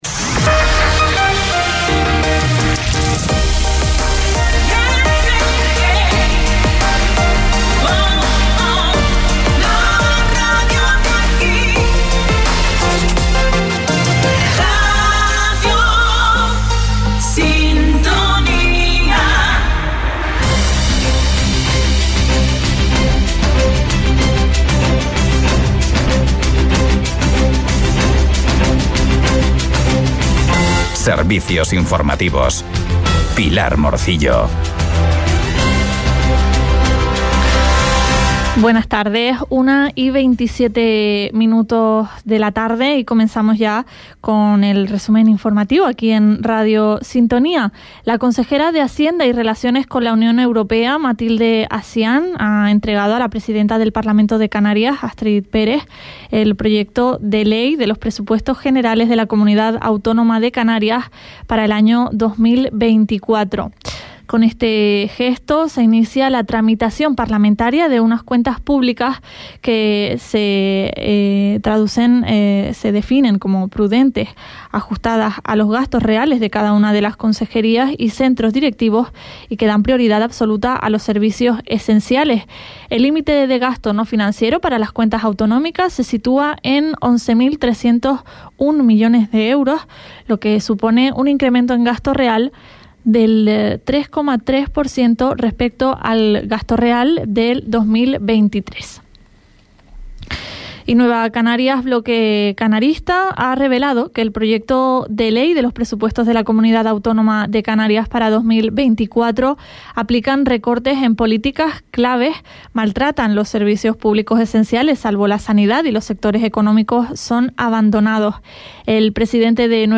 Informativos en Radio Sintonía - 02.11.23